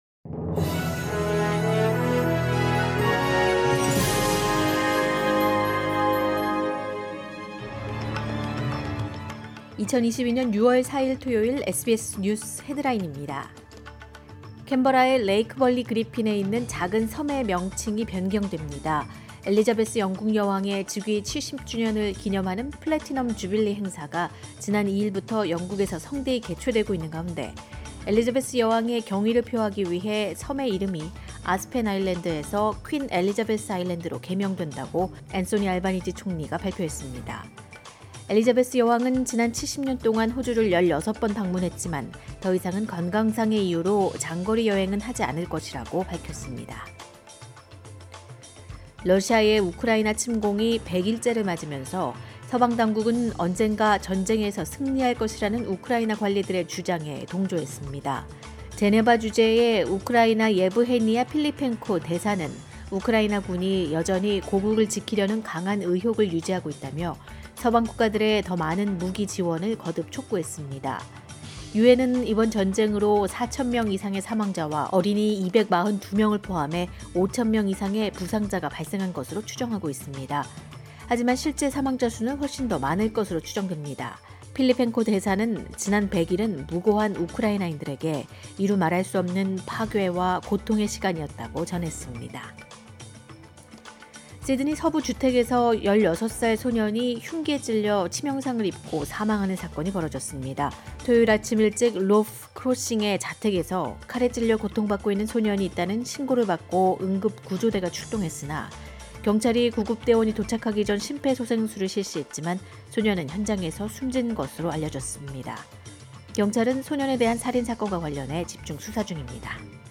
2022년 6월 4일 토요일 아침 SBS 한국어 간추린 주요 뉴스입니다.